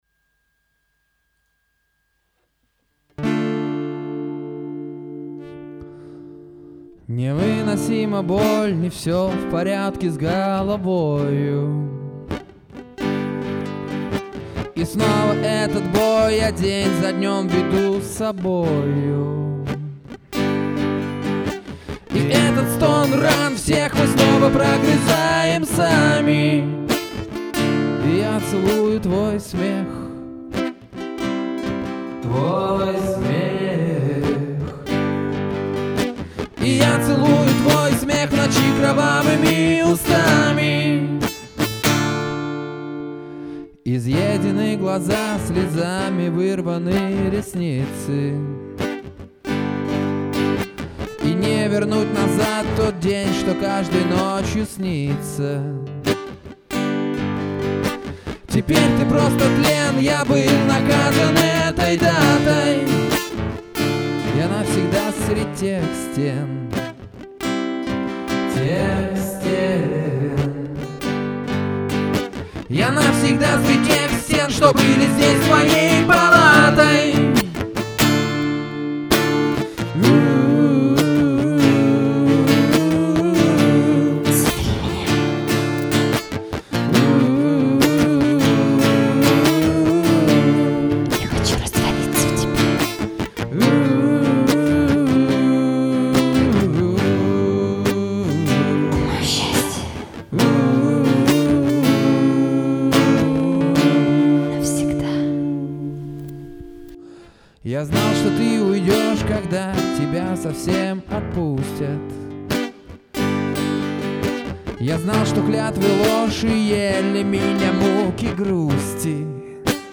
Авторская песня /
art акустика